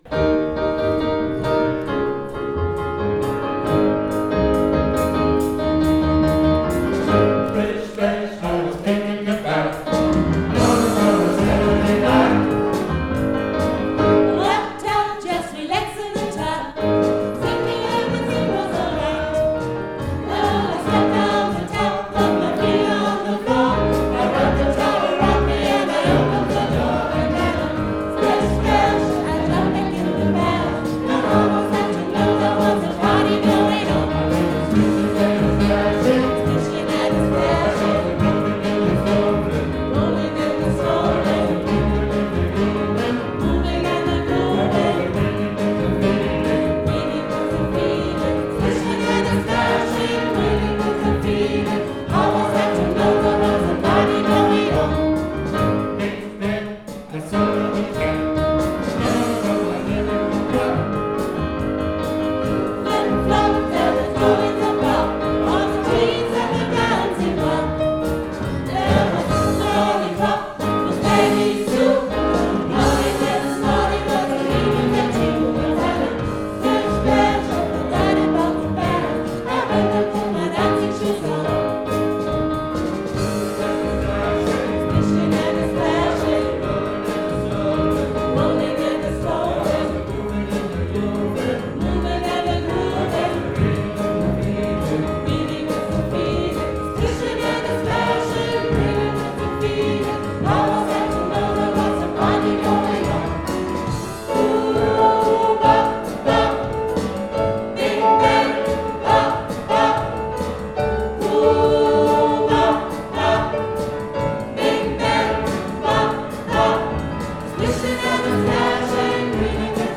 Jubilu�umskonzert 2013
Chor